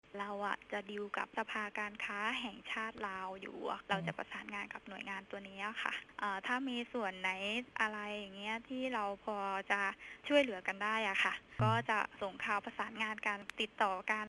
ເຈົ້າໜ້າທີ່ຈາກສະພາທຸຣະກິດ ໄທ-ລາວ ທ່ານນຶ່ງ ກ່າວກ່ຽວກັບເລື່ອງນີ້ວ່າ: